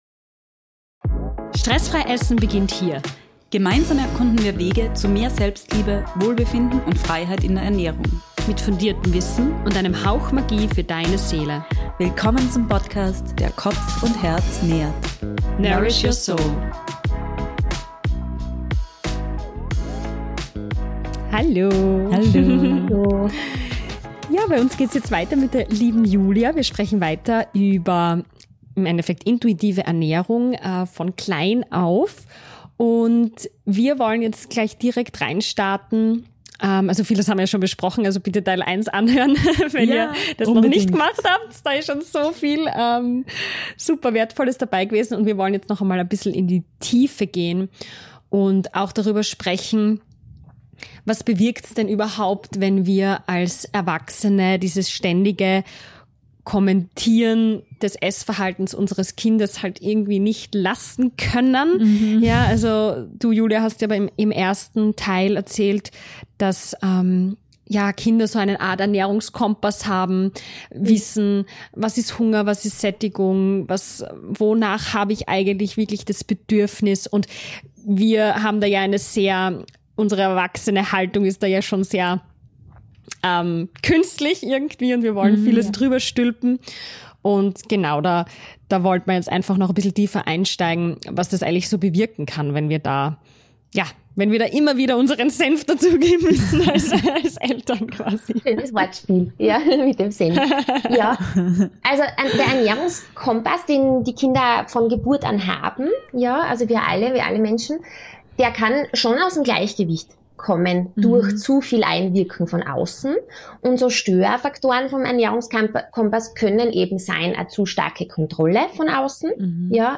Hör rein in den zweiten Teil dieses spannenden Interviews!